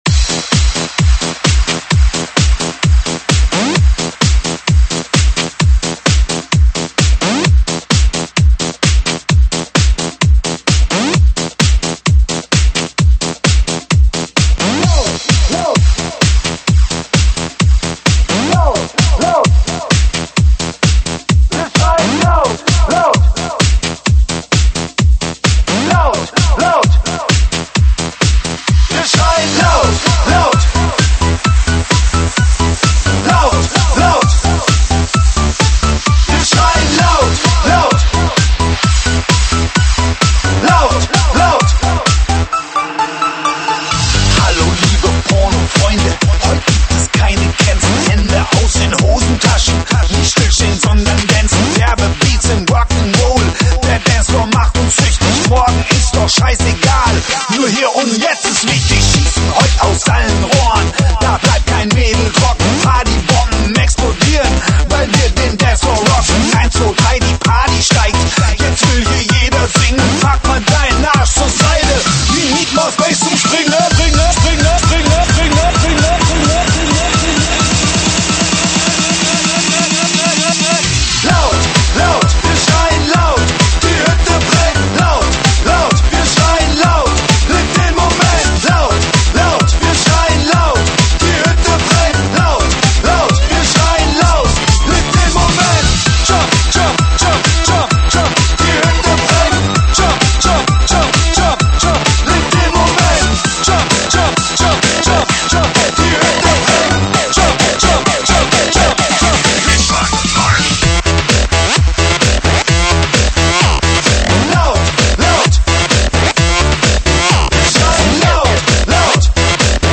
栏目：慢摇舞曲